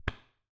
冲压 " 冲压005
描述：打孔的声音。
Tag: SFX 打孔 一巴掌